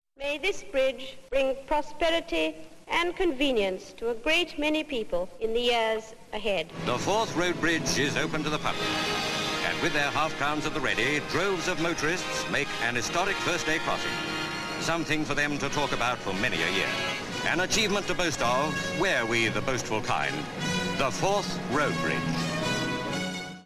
Here is the moment the Queen cut the ribbon on the crossing along with a news report from the day.